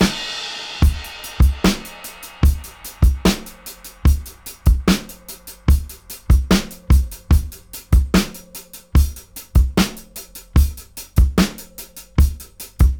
73-DRY-03.wav